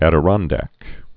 Ad·i·ron·dack chair
(ădə-rŏndăk)